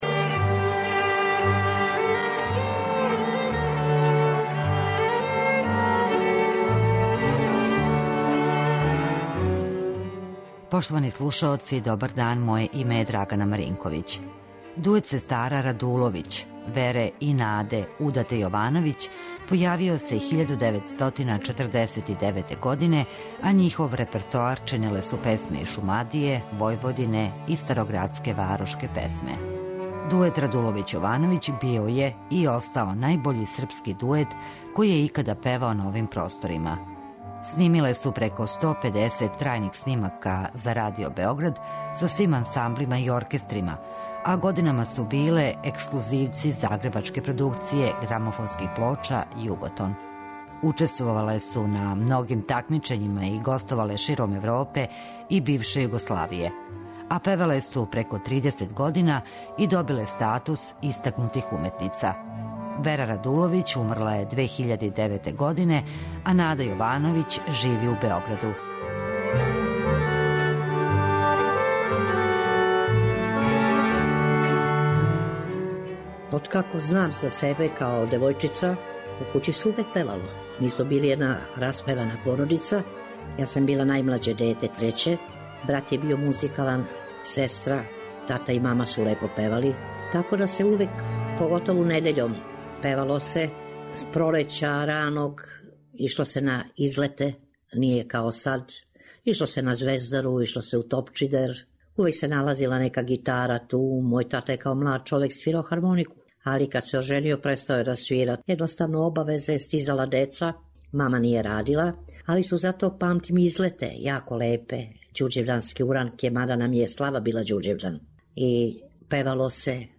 Данашњи музички портрет посветили смо дуету сестара
Појавиле су се 1949. године, а њихов репертоар чиниле су песме из Шумадије, Војводине и градске песме. Снимиле су преко сто педесет трајних снимака за Тонски архив Радио Београда, са свим ансамблима и оркестрима.